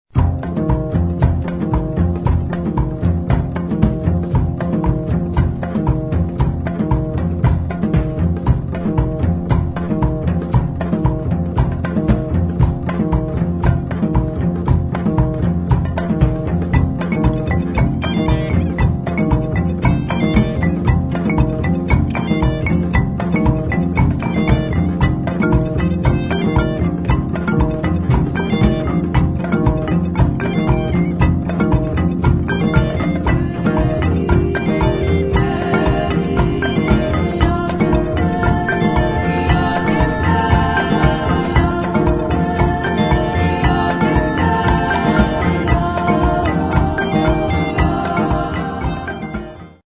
[ JAZZ | ROCK | FUNK | WORLD ]